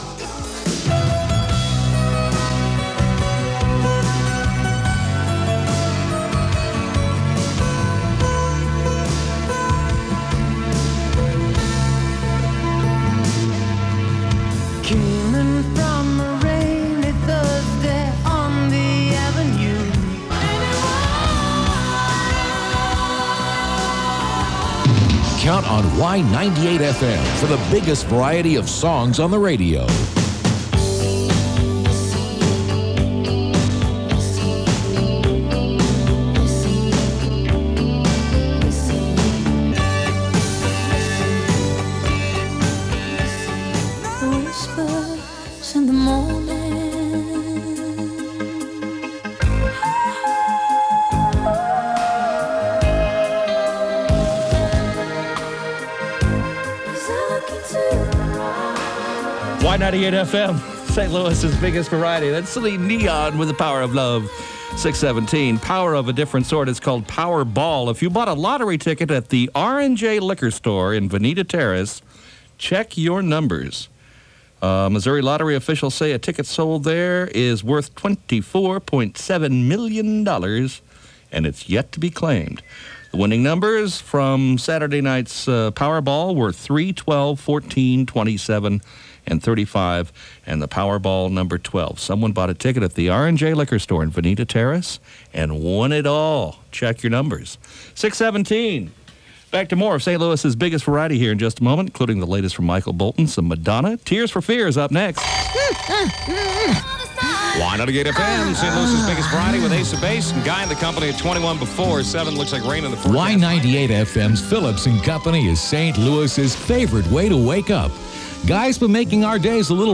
KYKY Aircheck · St. Louis Media History Archive